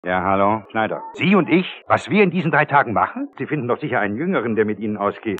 Hörprobe des deutschen Synchronschauspielers